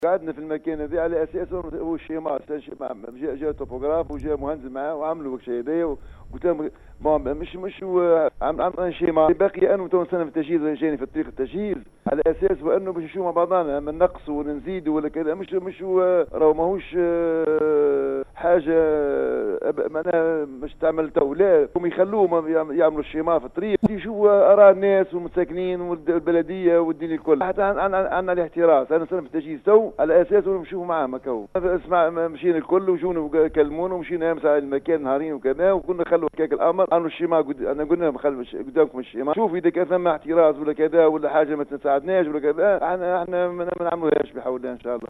وفي اتصالنا برئيس بلدية حيدرة كمال بيالضيافي أكد أن الشكل الذي وضعته البلدية هو شكل مبدئي وتم وضعه لمعرفة النقائص و مدى مطابقته للسلامة المرورية وهو قابل للتغير ولن يكون نهائي إلا بعد موافقة جميع الأطراف من مستعملي طريق ومواطنين والبلدية وبعد التأكد من مراعاة سلامة المواطنين قبل كل شئ.
رئيس بلدية حيدرة كمال بالضيافي